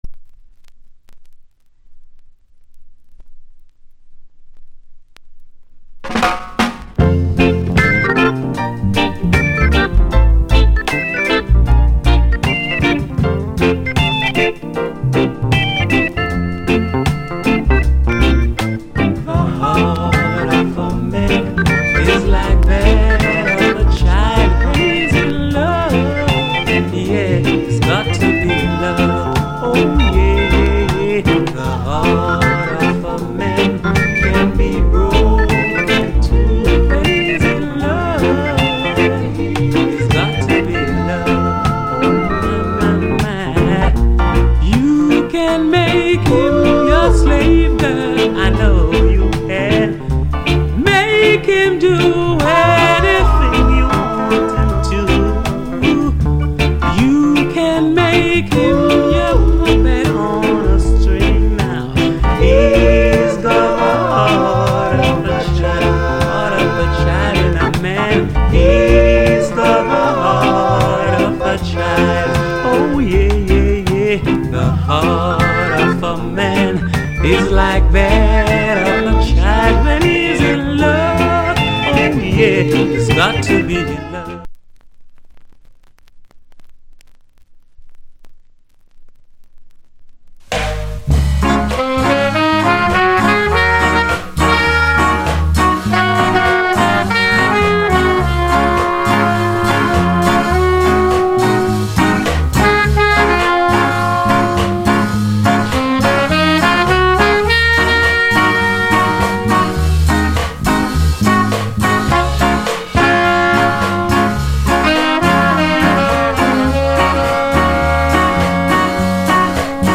7inch / Used / Reissue
Genre Early Reggae / [A] Male Vocal Group Vocal [B] Inst
キラー・ロックステディ・インスト。''